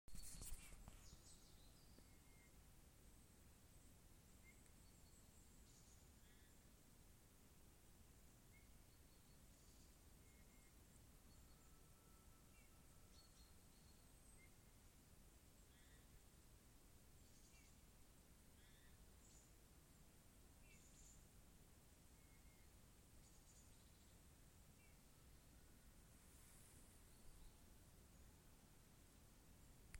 Bullfinch, Pyrrhula pyrrhula
Ziņotāja saglabāts vietas nosaukumsKalnišķu Graviņas
StatusVoice, calls heard